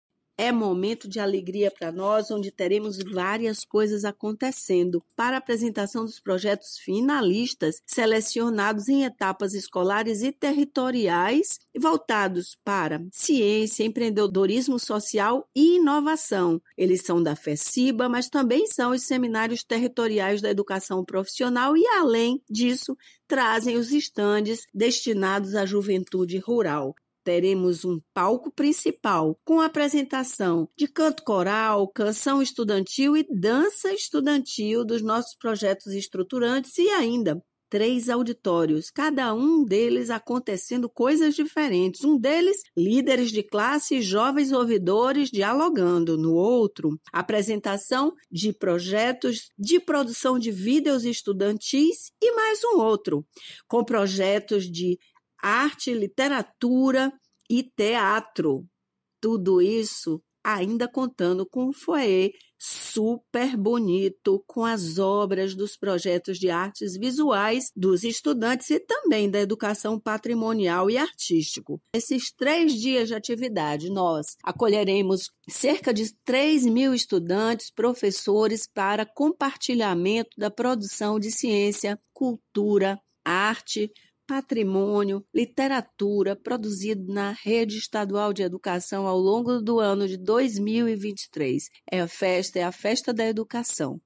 🎙Secretária Estadual de Educação, Adélia Pinheiro